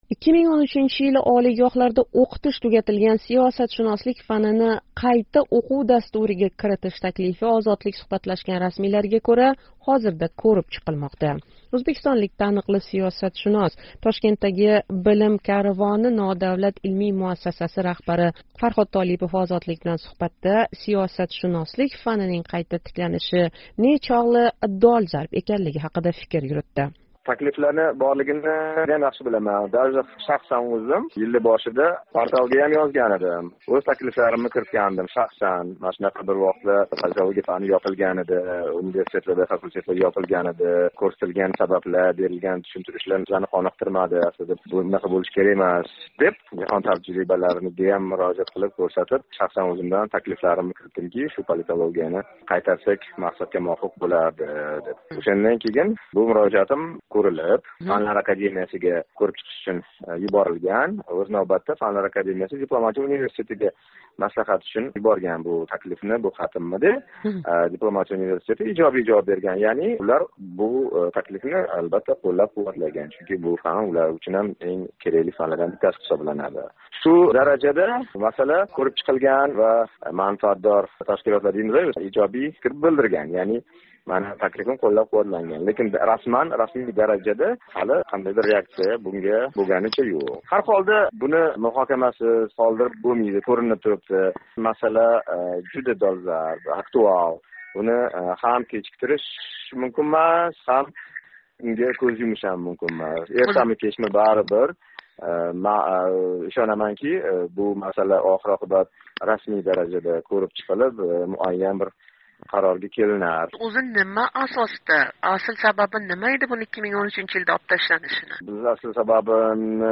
Suhbat davomida bu mutaxassis¸ O‘zbekistonda siyosiy islohotlar urinishi kuzatilayotgan hozirgi davrda siyosatshunoslik fanini o‘qitishning qayta tiklanishi o‘ta dolzarb ekanini alohida ta’kidlaydi.